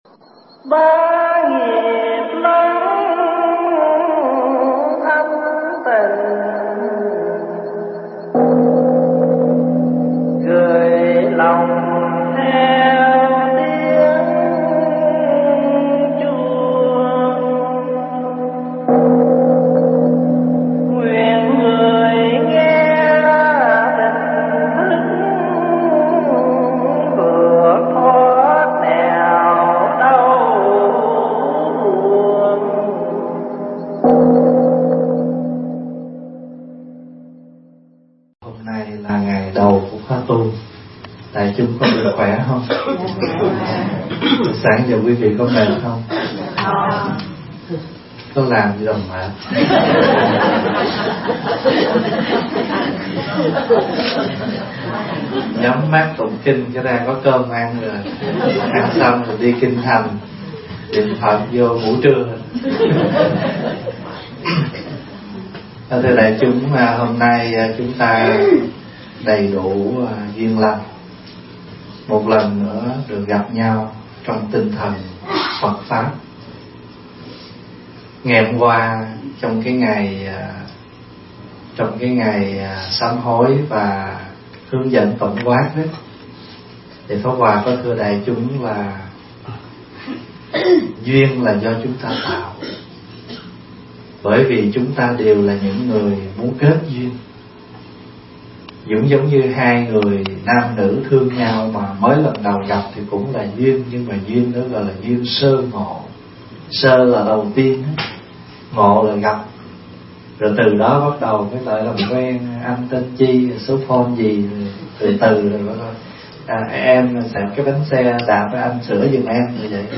Mp3 thuyết pháp Tu Gieo Duyên – Thầy Thích Pháp Hòa
Tải mp3 Thuyết Pháp Tu Gieo Duyên – Đại Đức Thích Pháp Hòa thuyết giảng tại Tu Viện Tây Thiên, Canada, ngày 16 tháng 4 năm 2015